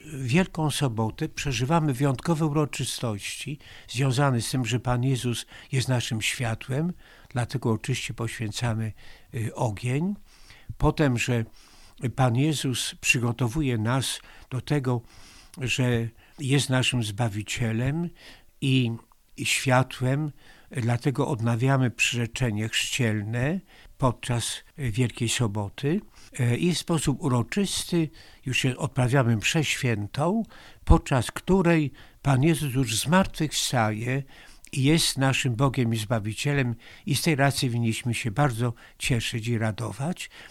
Jak bp Długosz wyjaśnia dzieciom co dzieje się podczas liturgii Wigilii Paschalnej?